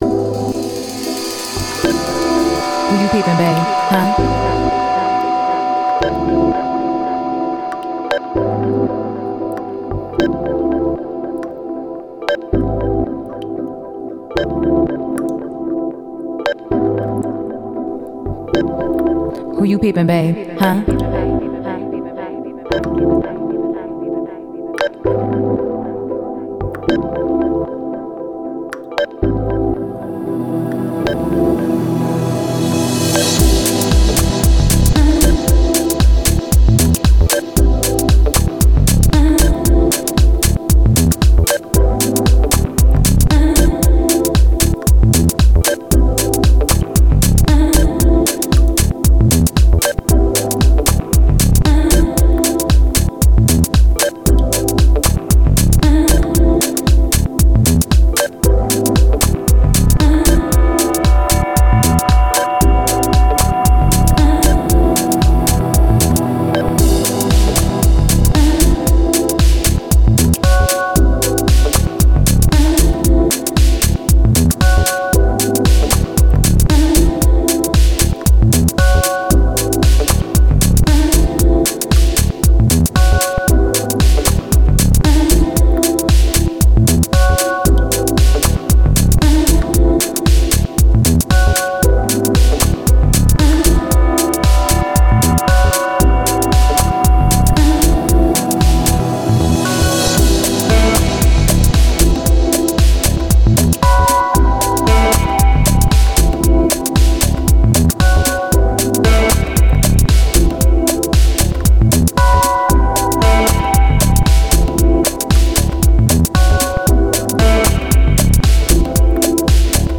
Genre: Deep House/Tech House.